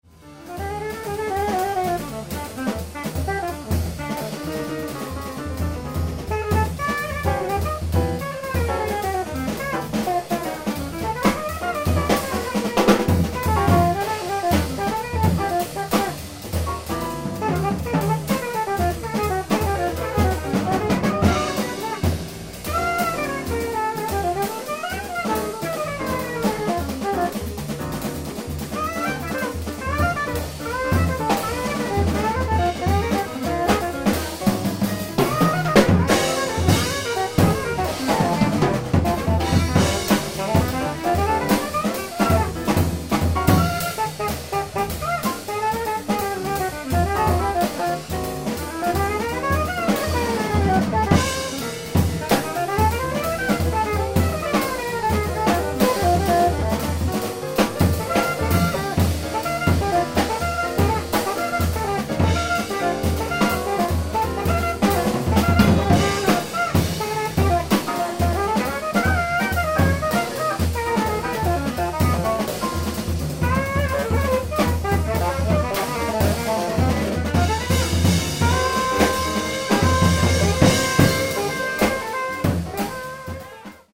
ライブ・アット・ブルーノート、ニューヨーク、04/02/1997
※試聴用に実際より音質を落としています。